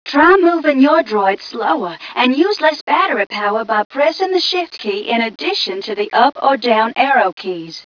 mission_voice_ghca030.wav